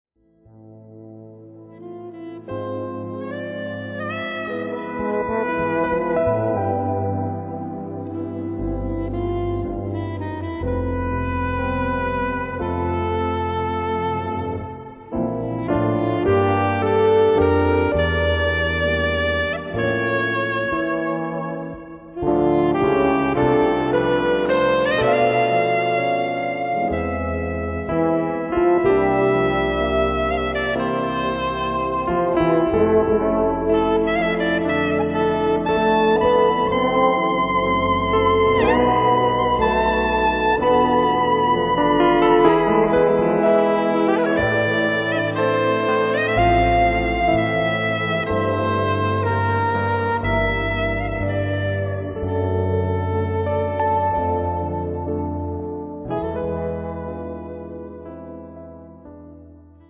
keyboards, vocals
4 strings bass, 6 strings bass, fretless bass
tenor + soprano sax, quena, flute,
drums, percussions